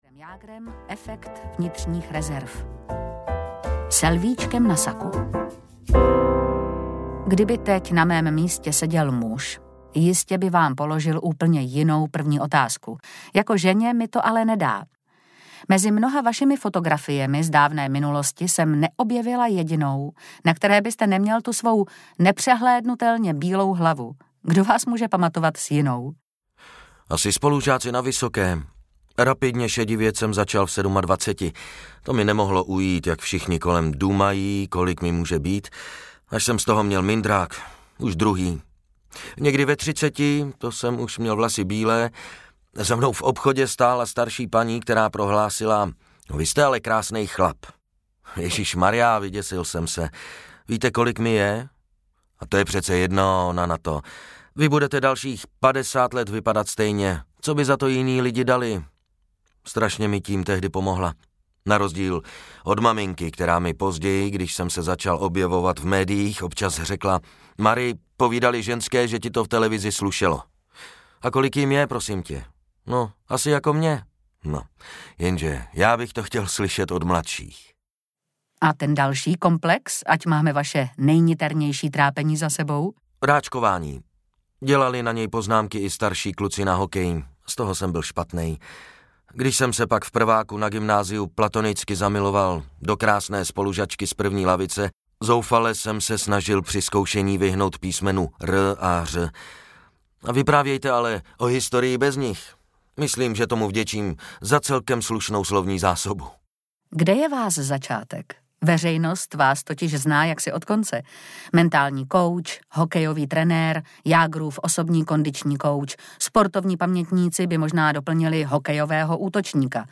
Život na uzdě audiokniha
Ukázka z knihy